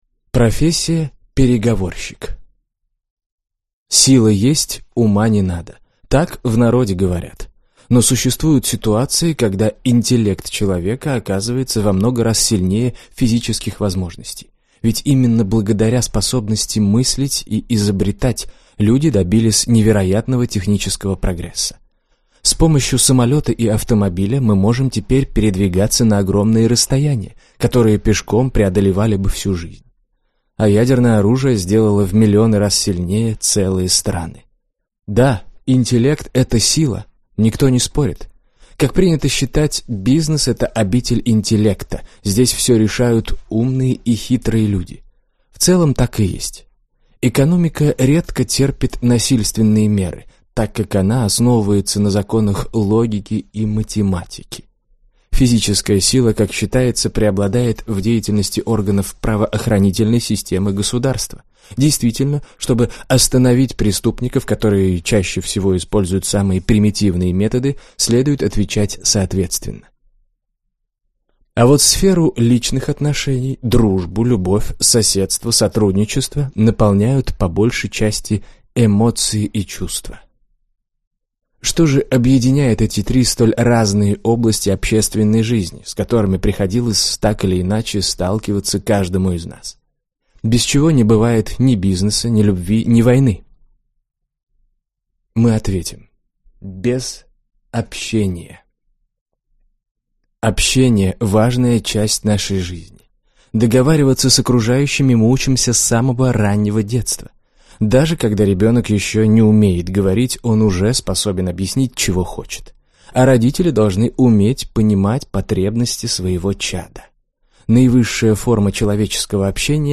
Аудиокнига Переговоры. Секретные методики спецслужб | Библиотека аудиокниг